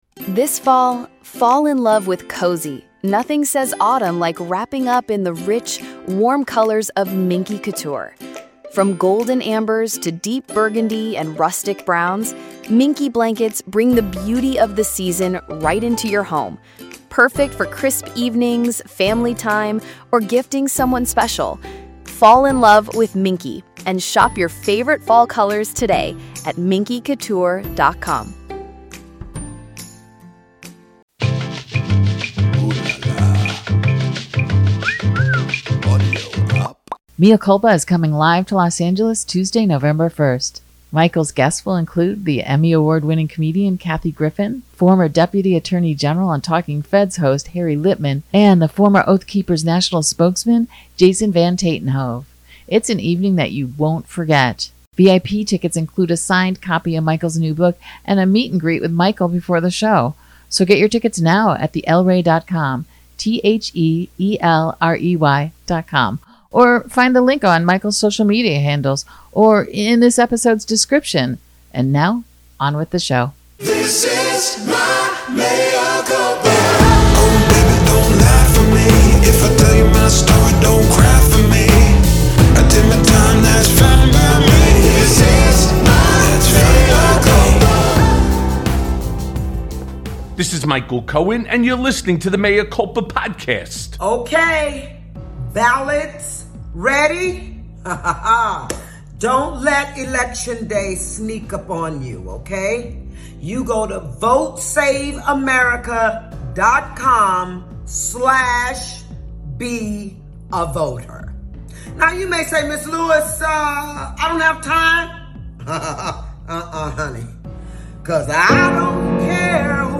Mea Culpa welcomes back the author and cultural analyst Molly Jong-Fast.
Michael and Molly don't hold back as they discuss the upcoming midterms, the future of Trump, and women's rights.